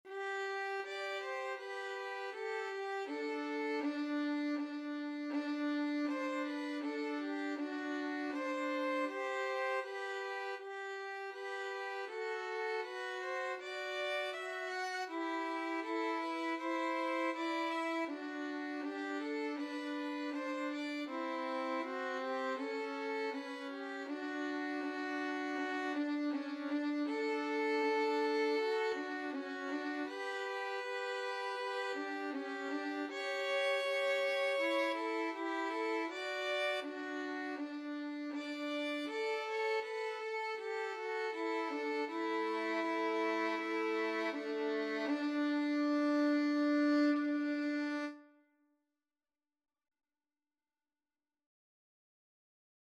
Largo =80
Violin Duet  (View more Easy Violin Duet Music)
Classical (View more Classical Violin Duet Music)